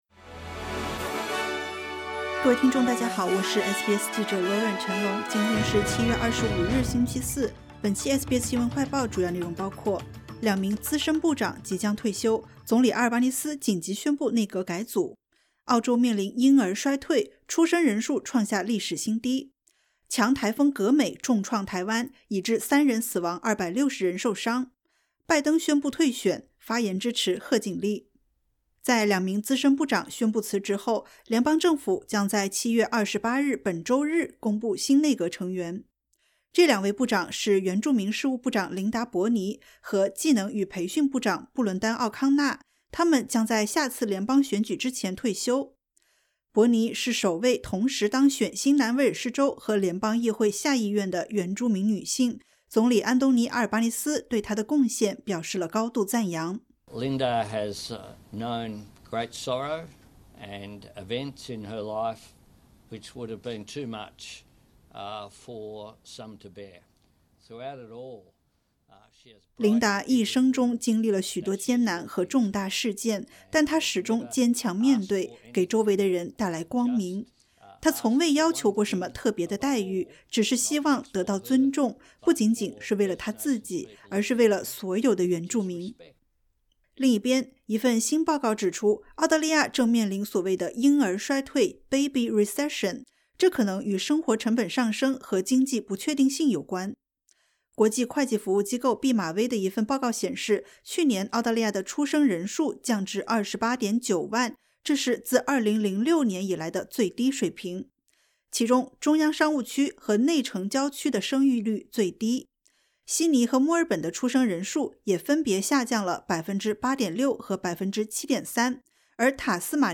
【SBS新闻快报】两名资深部长即将退休 总理紧急宣布内阁改组